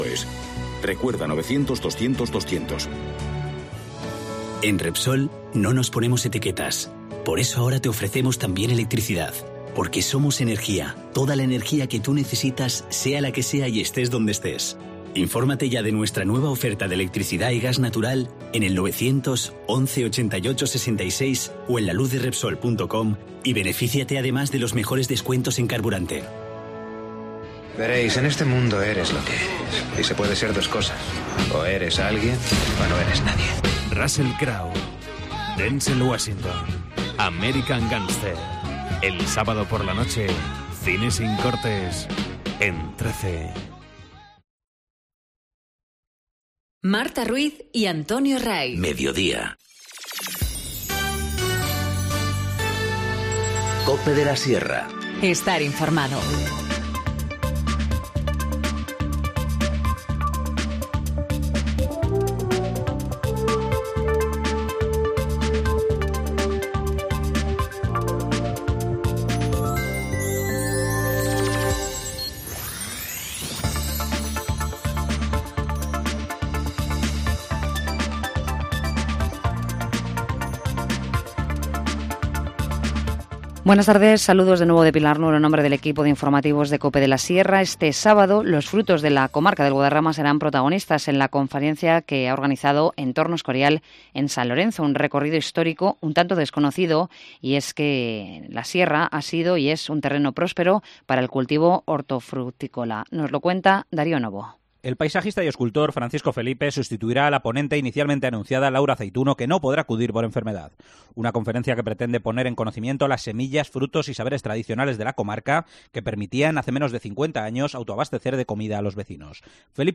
Informativo Meediodía 17 enero- 14:50h